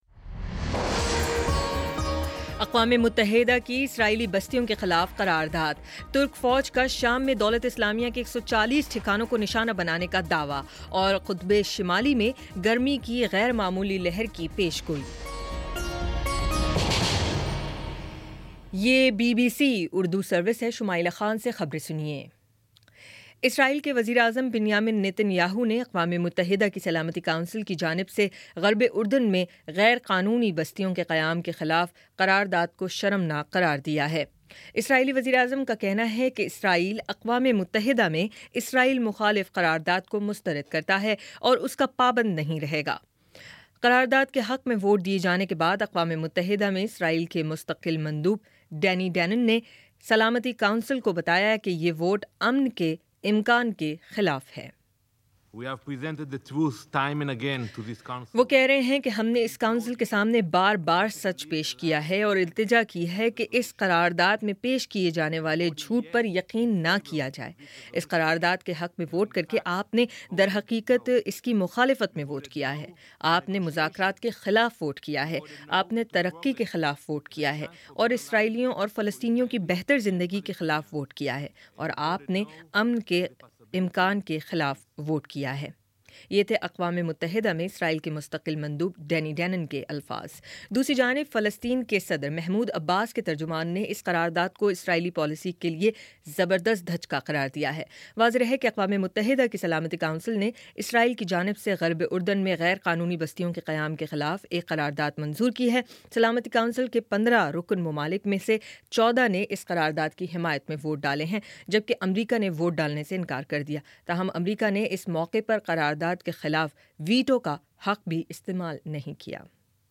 دسمبر 24 : شام پانچ بجے کا نیوز بُلیٹن